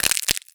STEPS Glass, Walk 13.wav